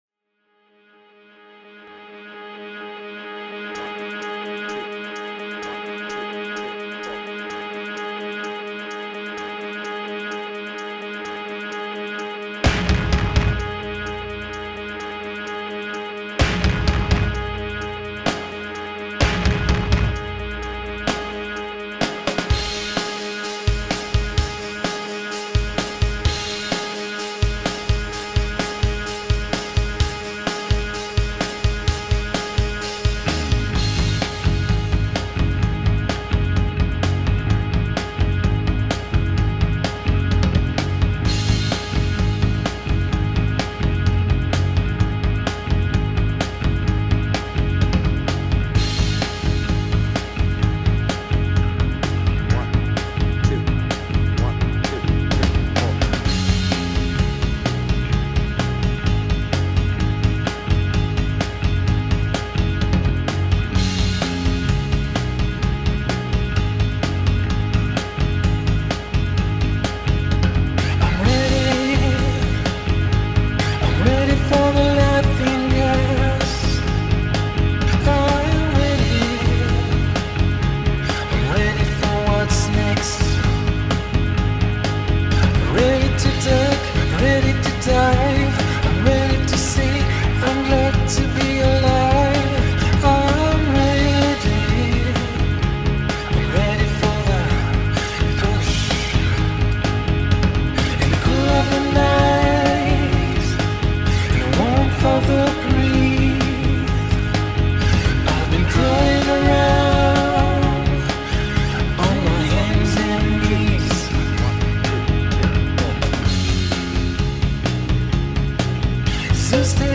BPM : 128
Tuning : Eb
Without vocals